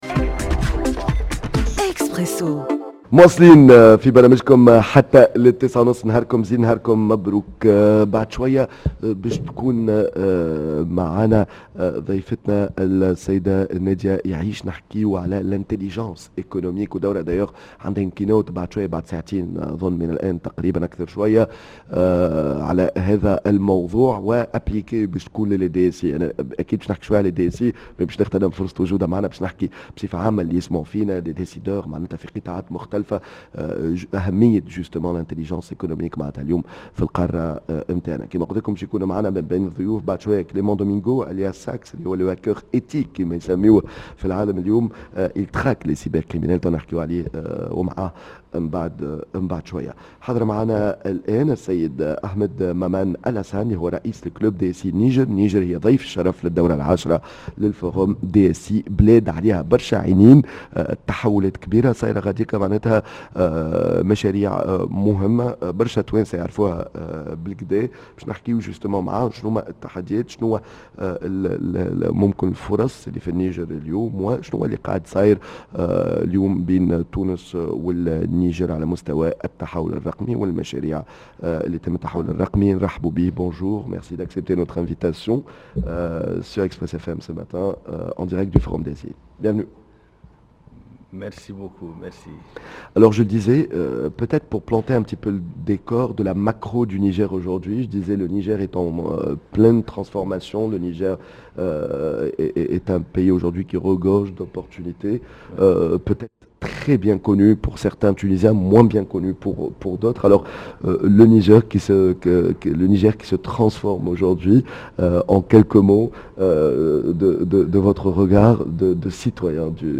dans un plateau spécial en direct de Yasmine El Hammamet